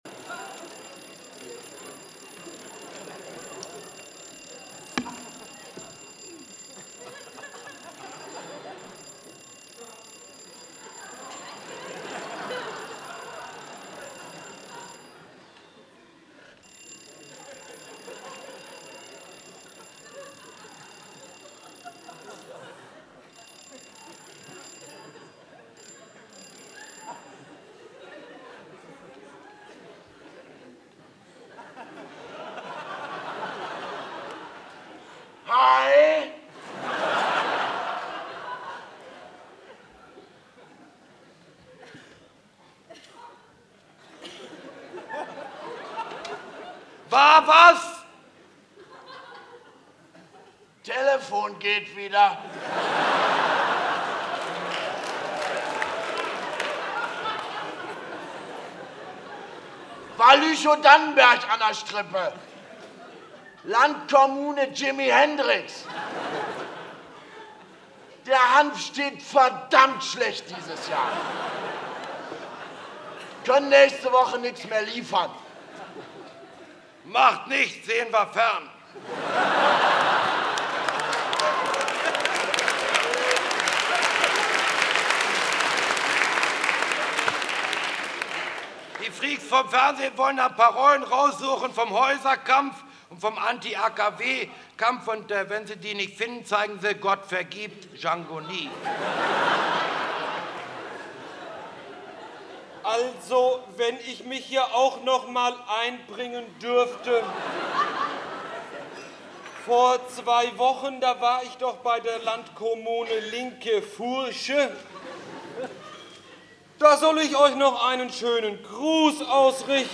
Live-Mitschnitt vom 17.06.1979 im Quartier Latin Berlin